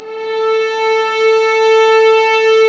Index of /90_sSampleCDs/Roland LCDP13 String Sections/STR_Violins II/STR_Vls6 p%mf M